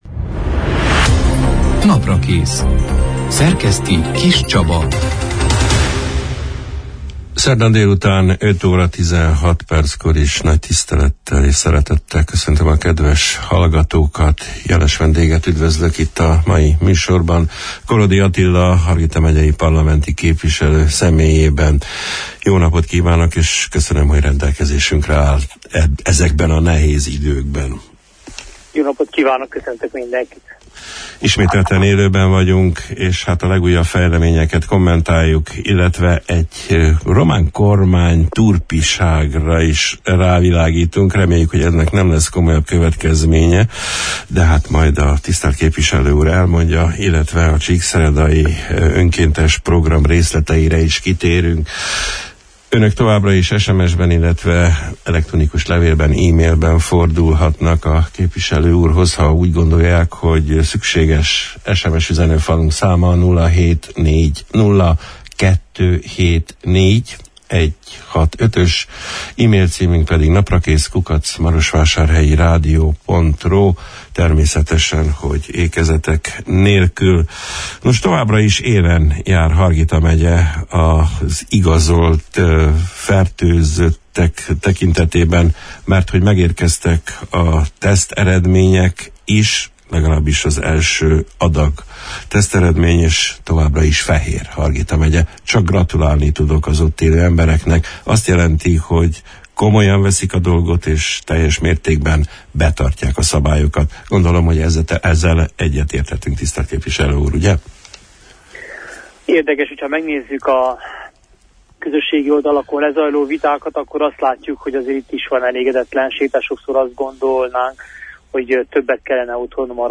A napi vírushelyzettel kapcsolatos adatok mellett, egy, a román kormány által elkövetett turpiságra is rávilágítottunk, a március 25 – én, szerdán délután elhangzott élő Naprakész műsorban, amelyben vendégünk Korodi Attila parlamenti képviselő, Románia európa – parlamenti közgyűlésén részt vevő küldöttségének tagja volt.